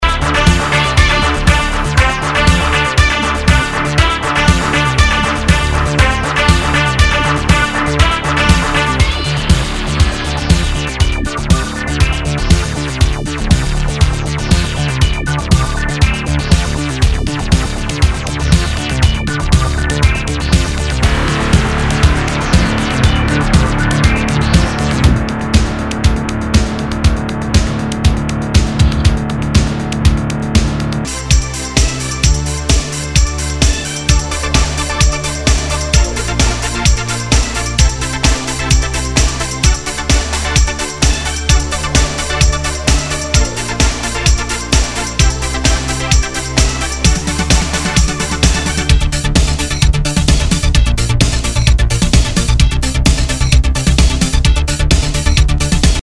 HOUSE/TECHNO/ELECTRO
ハードコア・テクノ・クラシック！